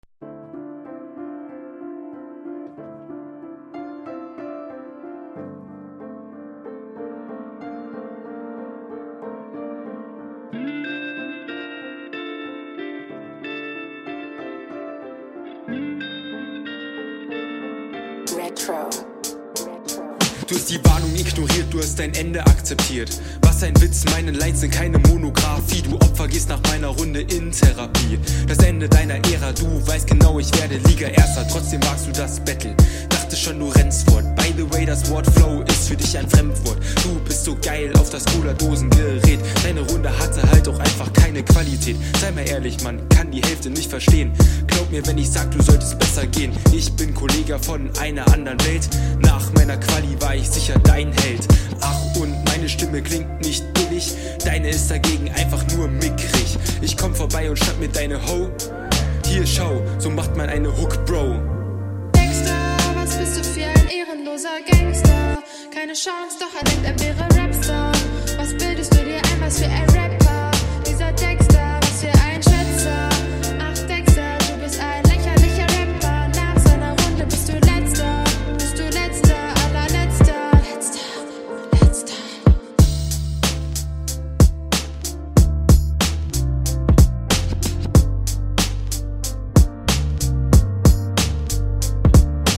Du kommst deutlicher auf den Beat. und flowst auch besser.